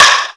whack.wav